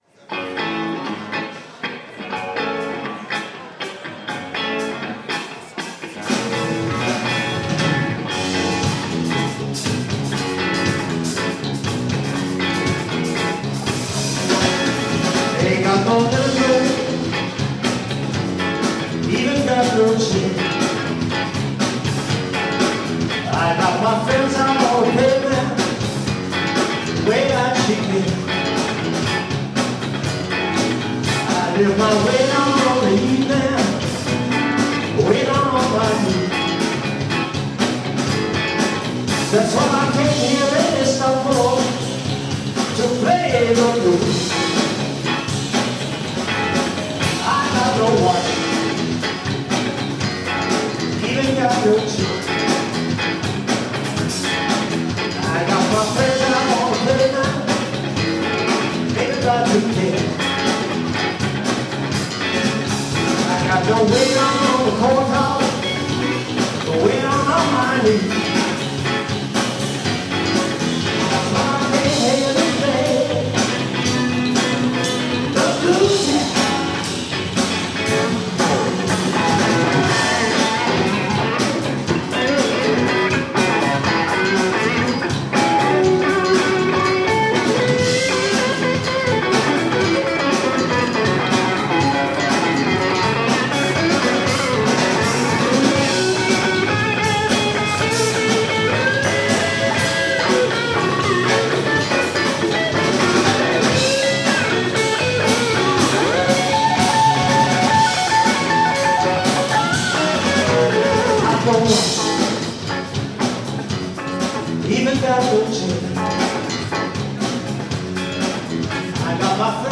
"SCHOOL OF ROCK" - Rockkonzert an unserer Schule
Im Rahmen eines Maturaprojektes fand am 14.2.2013 im Theatersaal des Österreichischen St. Georgs-Kolleg ein gut besuchtes Konzert unter dem Titel "SCHOOL of ROCK" statt.